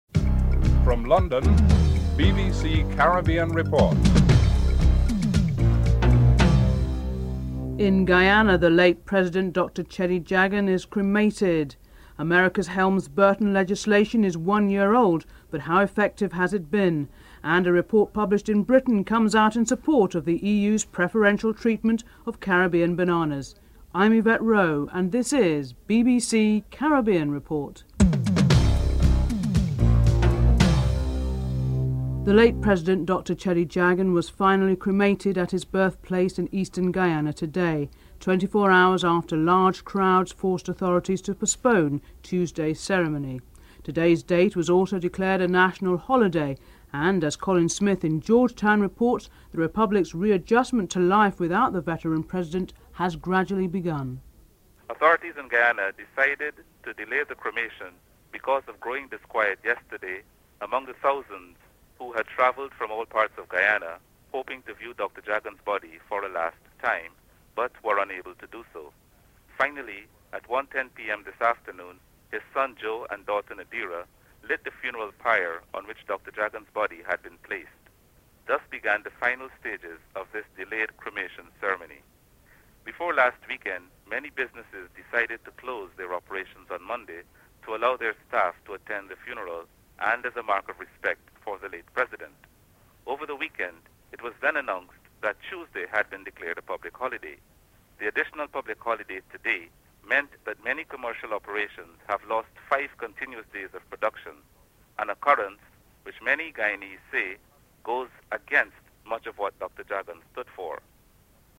4. The main political parties, in the Bahamas, are gearing up for elections. Prime Minister, Hubert Ingraham is interviewed (10:12-12:14)